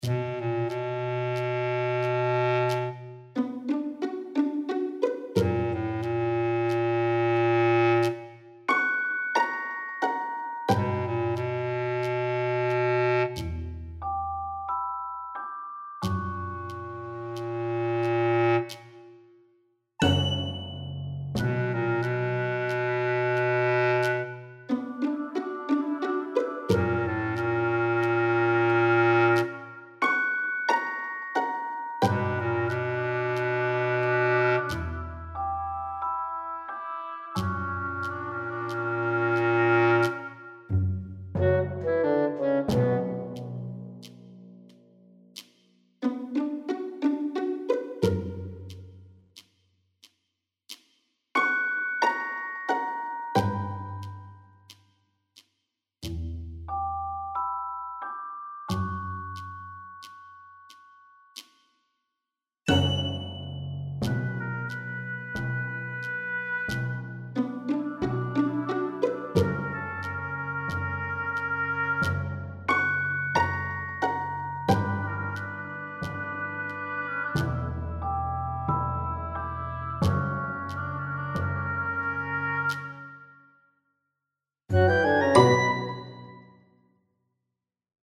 ファンタジー系フリーBGM｜ゲーム・動画・TRPGなどに！
（腹痛と）戦ってそうな曲。